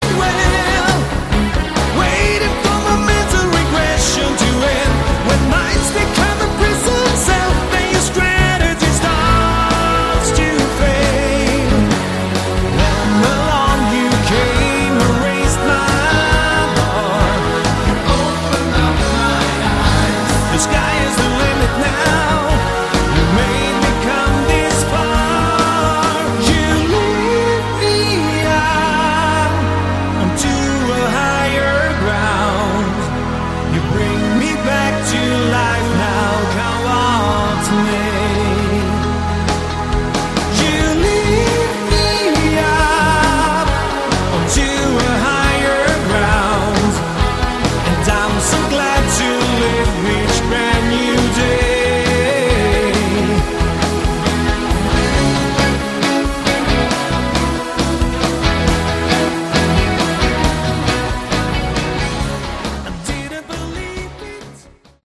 Category: AOR
vocals, keyboards
guitar, bass, keyboards, drums
guitar, programming